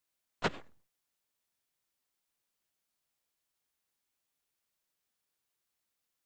FootstepsSnowCrnc WES095101
Snow Hiking; Single Crunch Footstep In Snow.